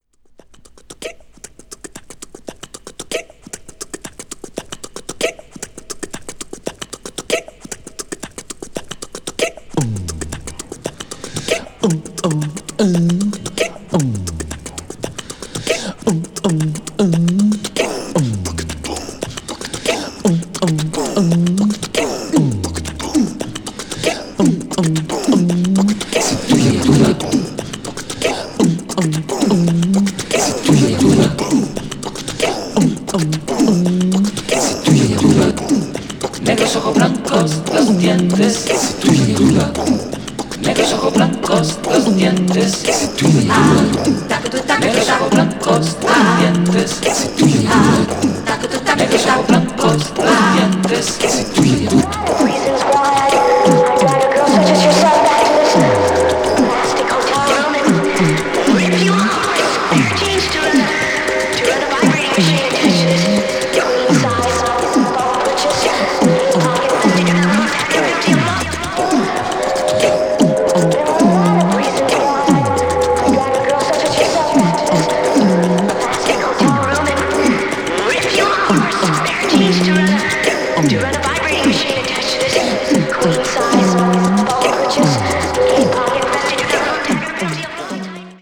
cosmic   disco related   funk   obscure dance   synth disco